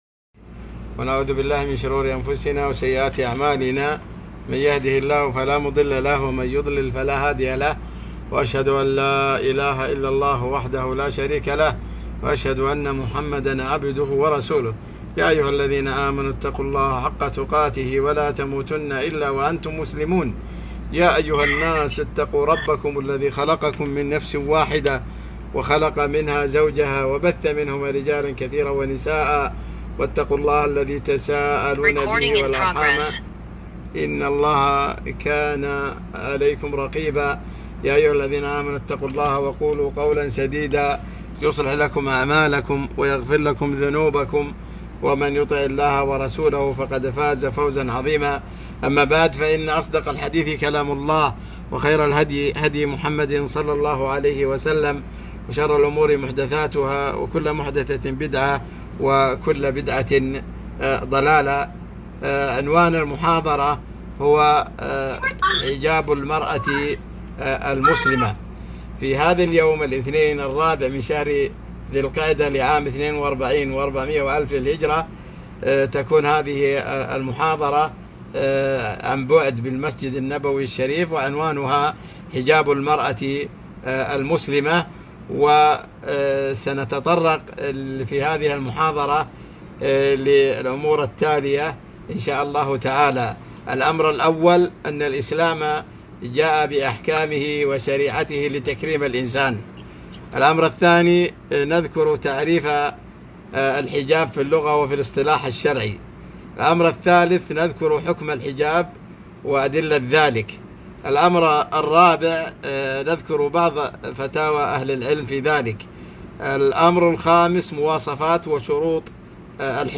محاضرة - حجاب المرأة المسلمة